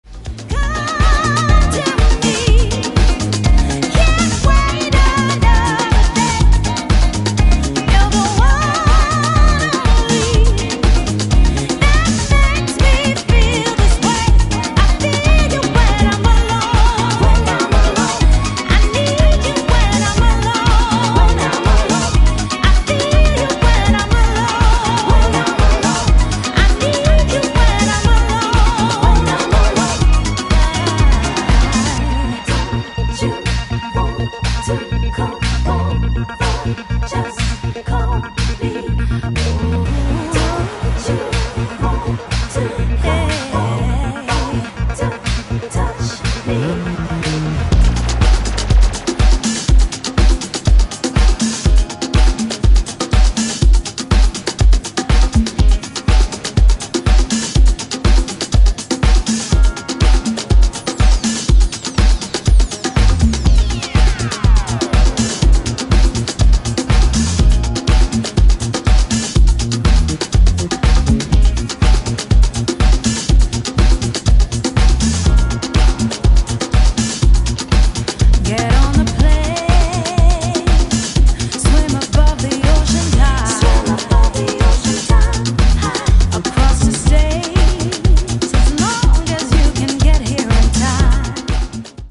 ジャンル(スタイル) NU DISCO / DISCO / HOUSE / RE-EDIT